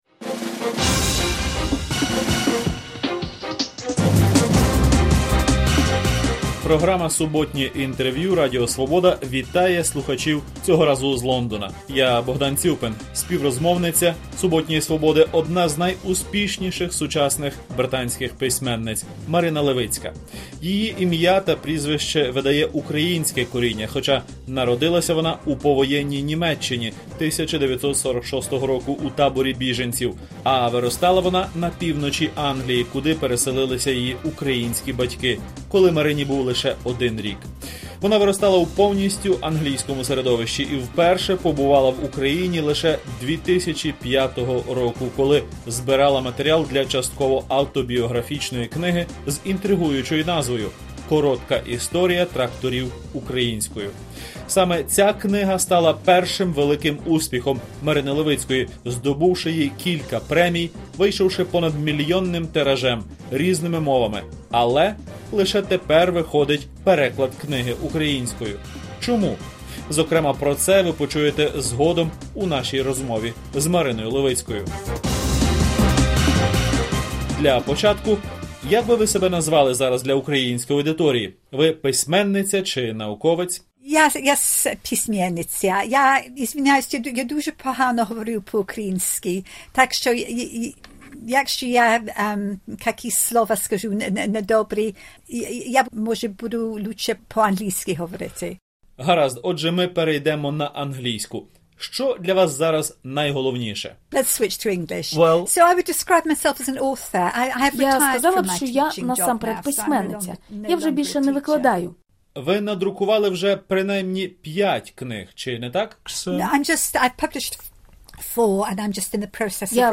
Суботнє інтерв’ю